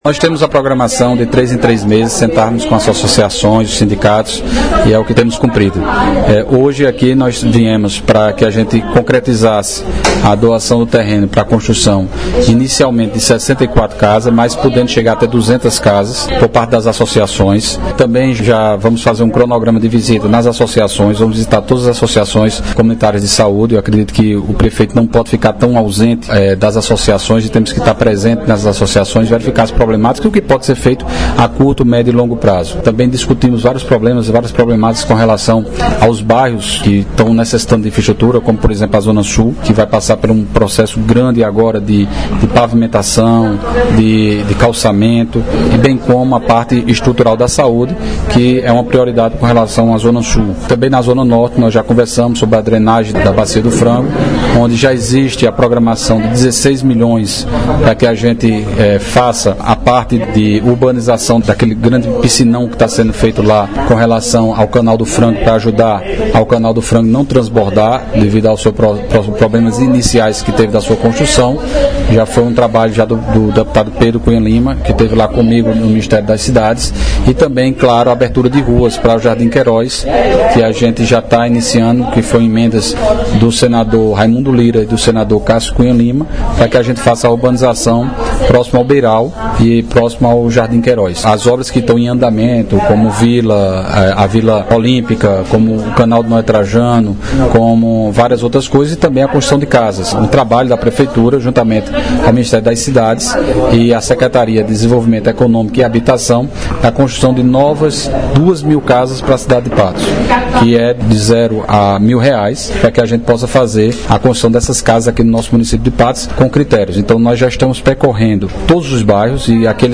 Fala do prefeito Dinaldinho Wanderley –